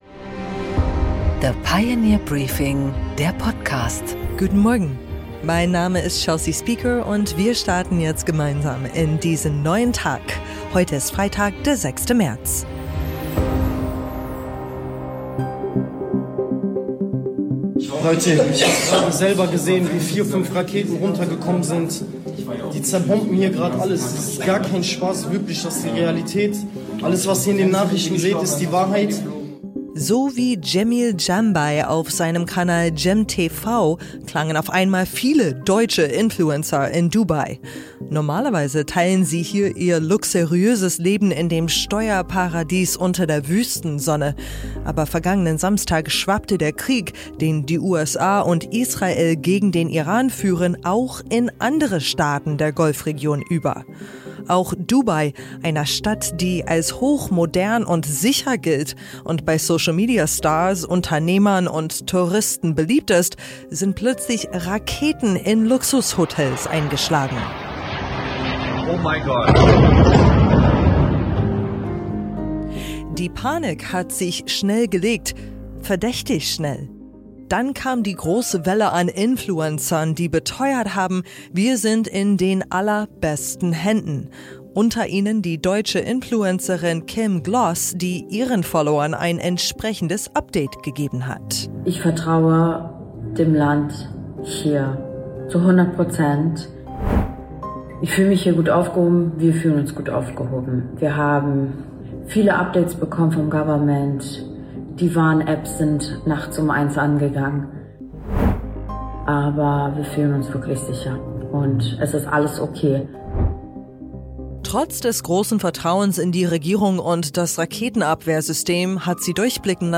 The Pioneer Briefing - Nachrichten